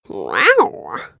growl